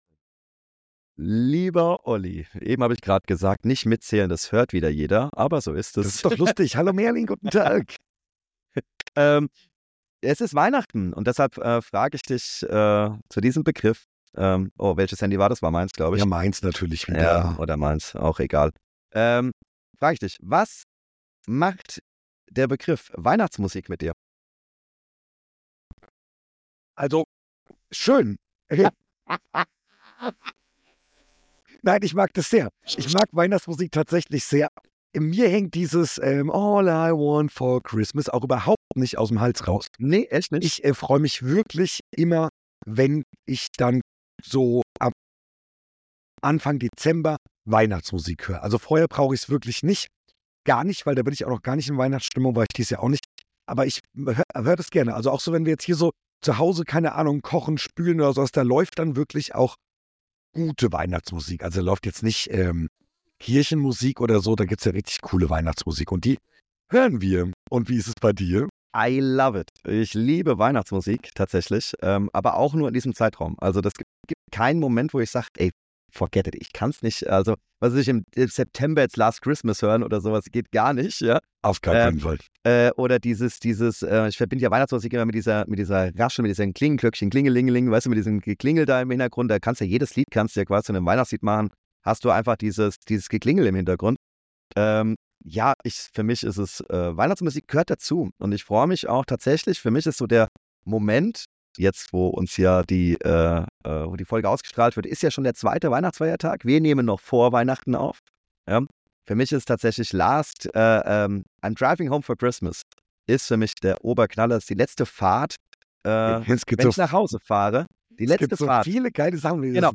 Erstmals in der Geschichte des Podcasts mit einer unvorhergesehenen Unterbrechung in der Folge - wie immer (fast) ungeschnitten, ungefiltert und roh!